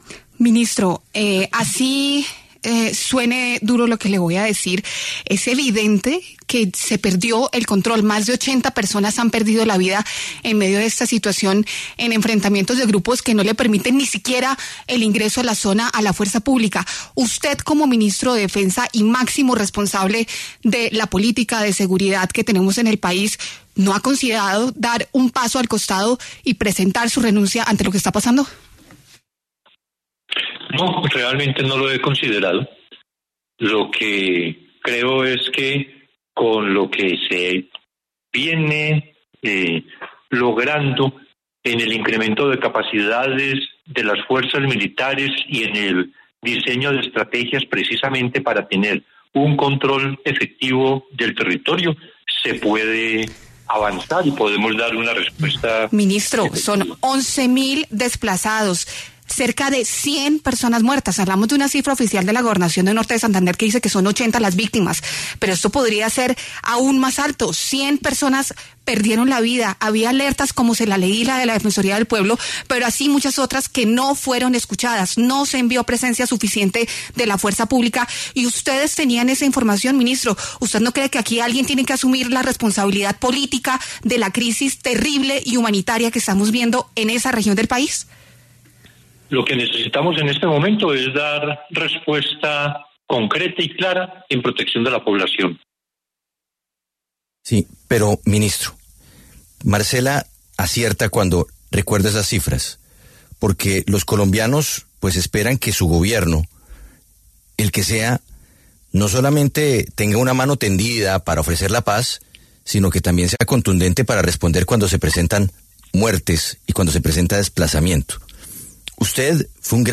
En diálogo con La W, Iván Velásquez, ministro de Defensa, afirmó que no ha considerado su renuncia en medio de la crisis humanitaria en el Catatumbo por cuenta de los combates entre el Ejército de Liberación Nacional (ELN) y las disidencias de las Farc.